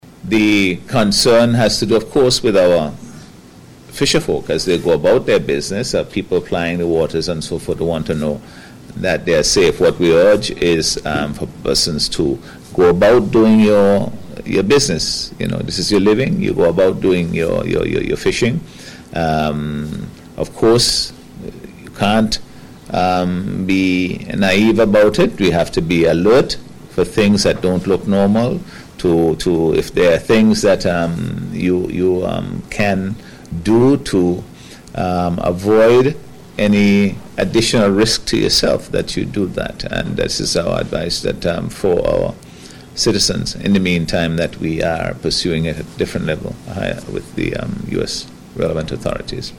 Word of this came from Prime Minister Dr. Hon Godwin Friday, during a News Conference on Tuesday, as he addressed fears over the Feb. 13 US strike on a fishing vessel in local waters, suspected of carrying cocaine.